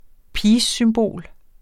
Udtale [ ˈpiːssymˌboˀl ]